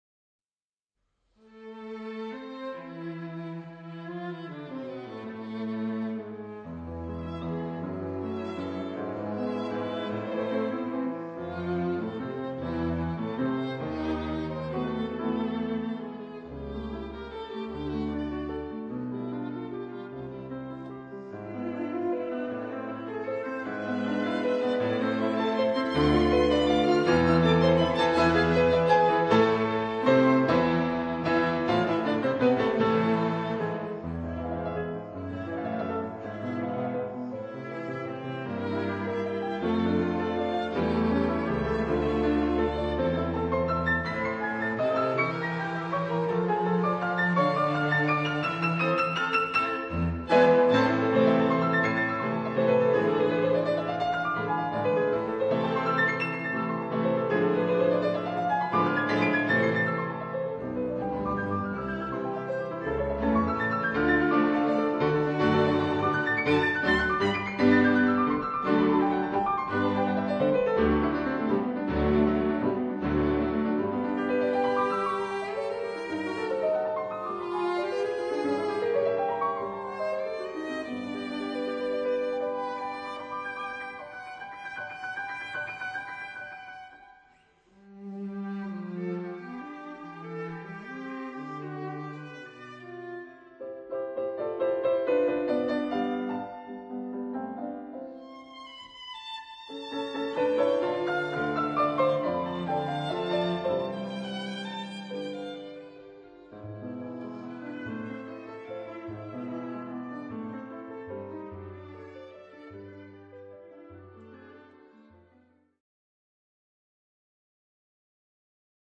»Superbe Klangqualität.«